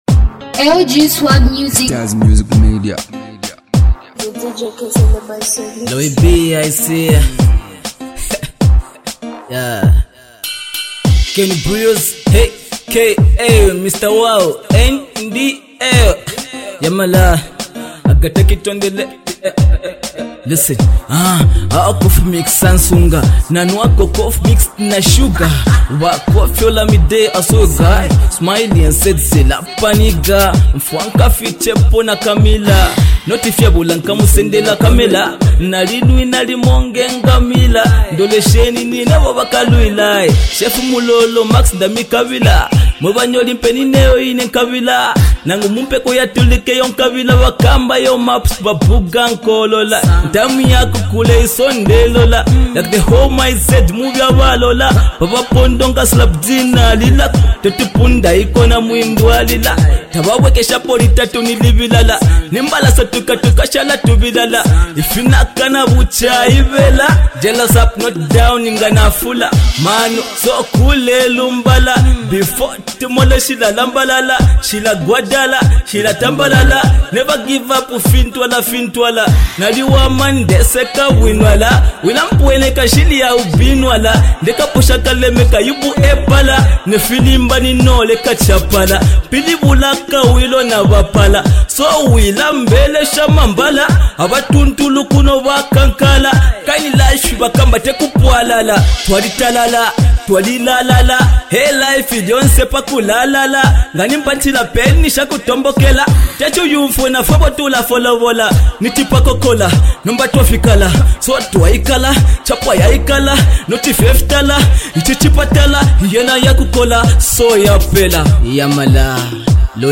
hot bars